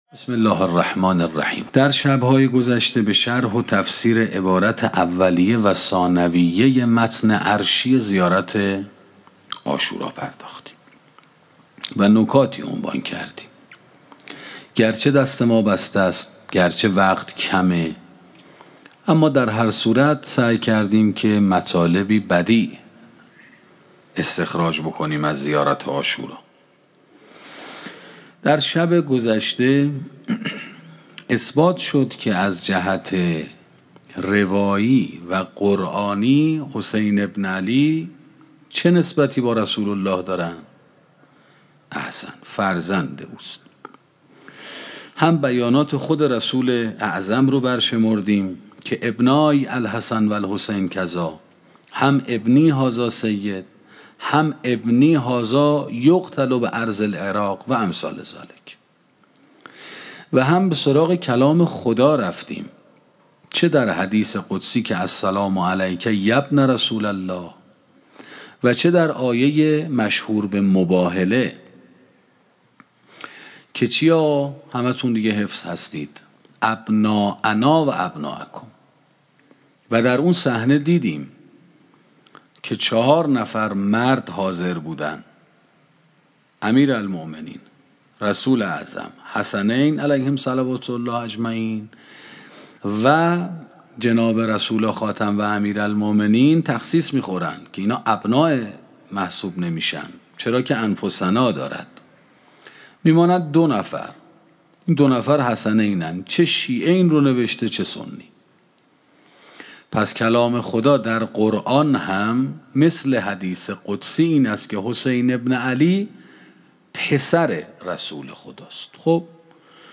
سخنرانی شرح زیارت عاشورا 14 - موسسه مودت